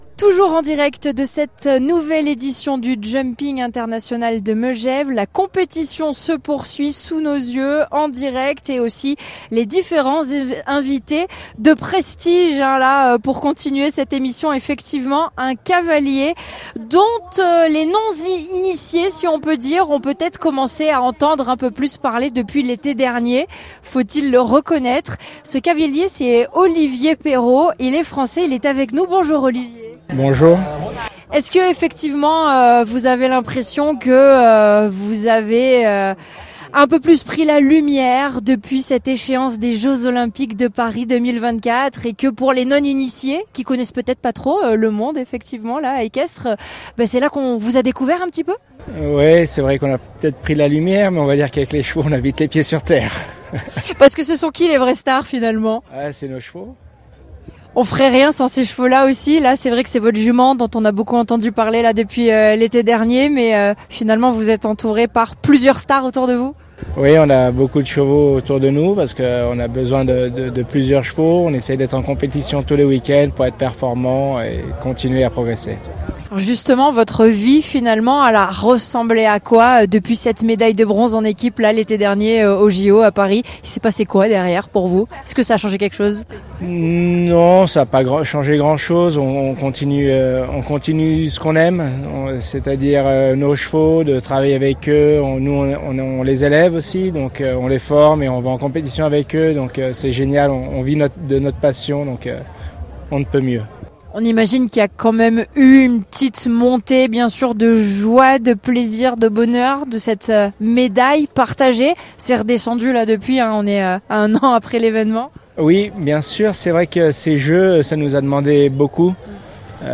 Partenaire de l’événement, nous étions en émission spéciale en direct, pour faire vivre aux auditeurs toute l’ambiance de cette grande fête du sport et de l’élégance.
Olivier Perreau, cavalier français, médaillé de bronze par équipe aux JO Paris 2024
Interview
JUMPING INVITE 3 OLIVIER CAVALIER.mp3